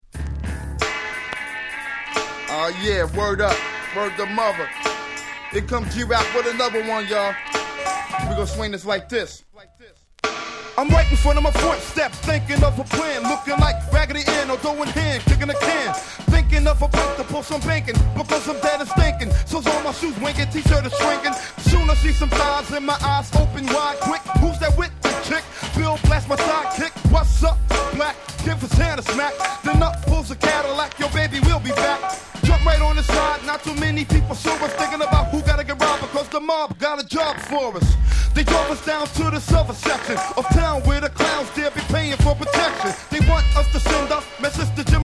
問答無用の90's Hip Hop Classic !!!!!
ド渋、そしてクソドープ。